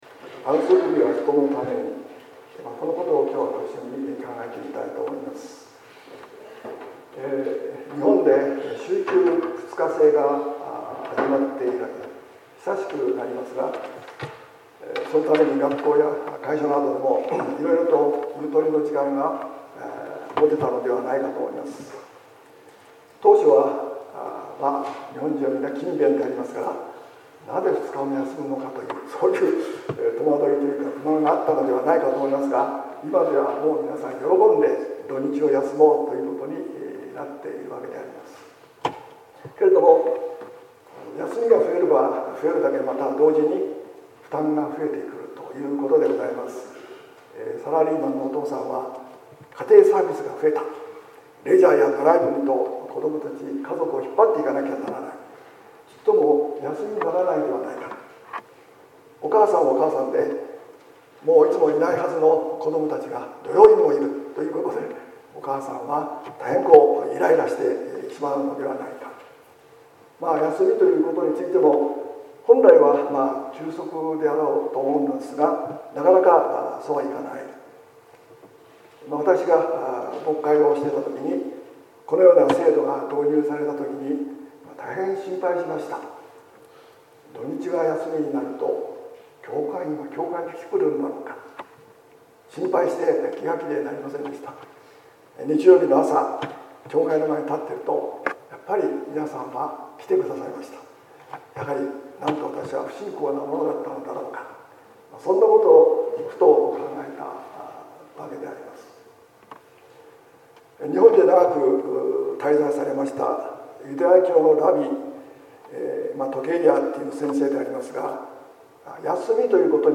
説教「安息日は人のために」（音声版）